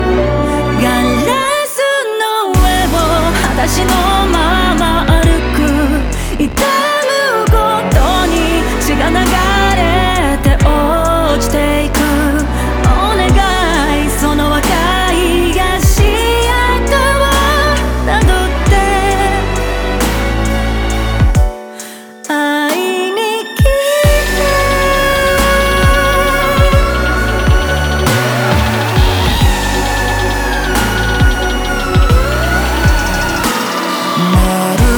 Жанр: Поп музыка
J-Pop